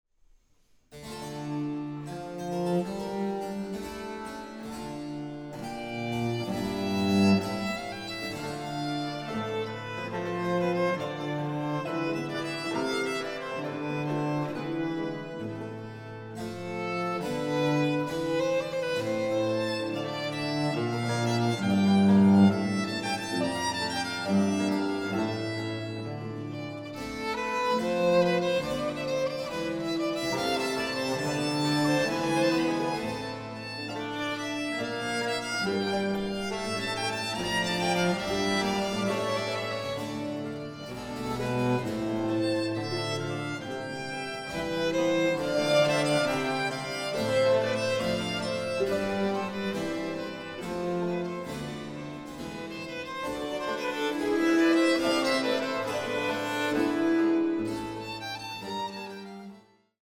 Baroque works on the theme of love in human and divine form
The finely balanced ensemble and the agile, expressive voice